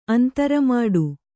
♪ antaramāḍu